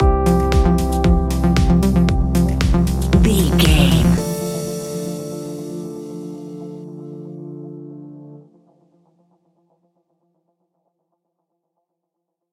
Modern Electronic Dance Pop Stinger.
Aeolian/Minor
groovy
uplifting
driving
energetic
repetitive
synthesiser
drum machine
electric piano
synth leads
synth bass